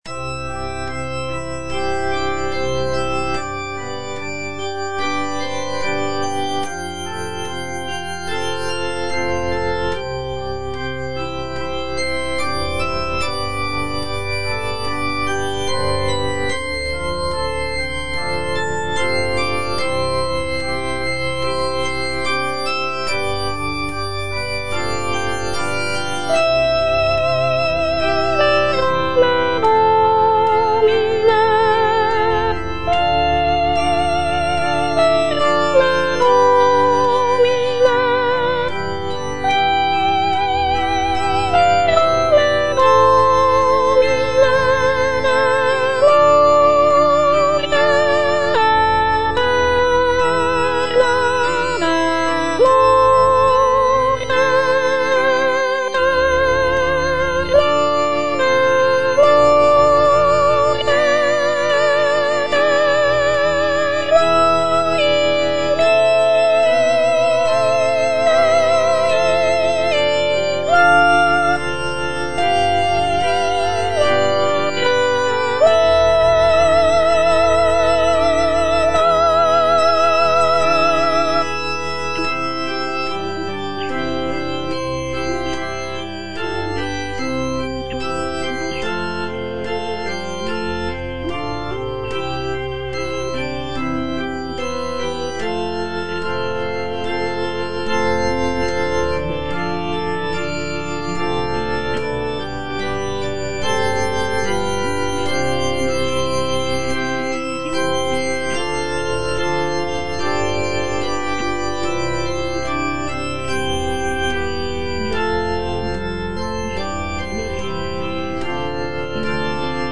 Soprano (Voice with metronome) Ads stop
is a sacred choral work rooted in his Christian faith.